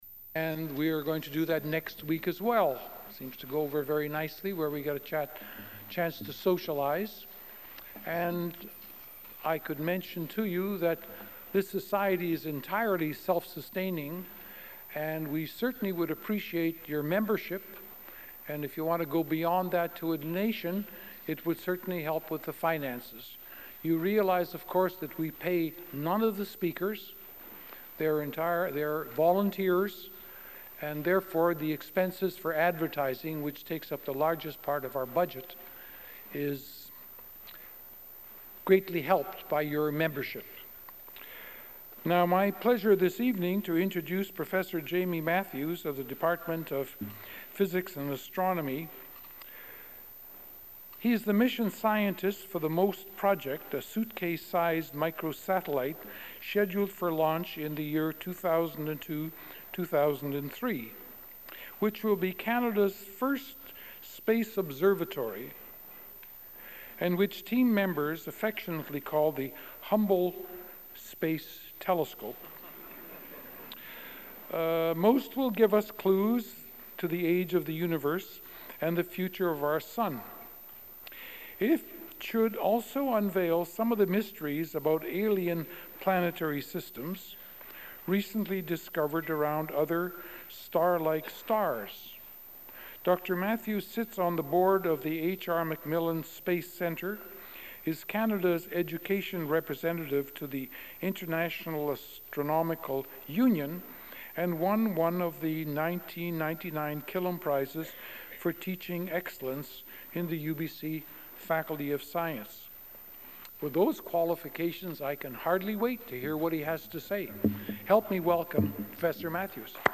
Original audio recording available in the University Archives (UBC AT 2477).